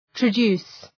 {trə’du:s}